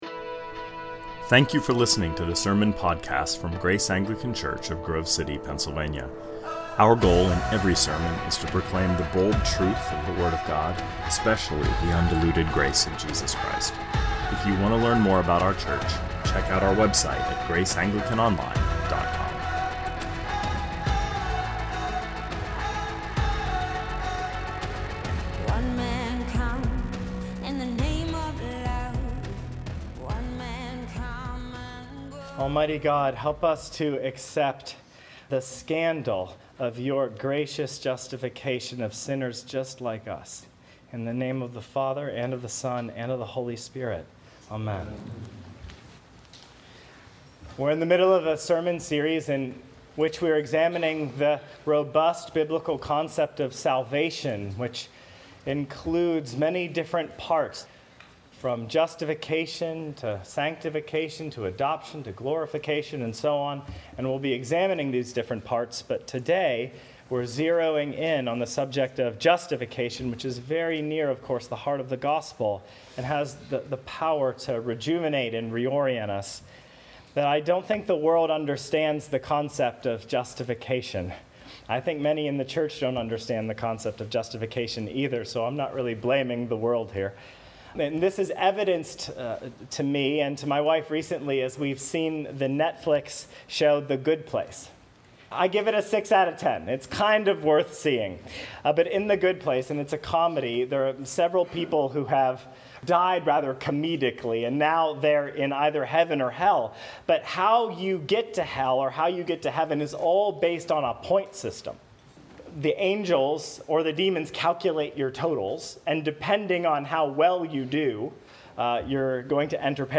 2019 Sermons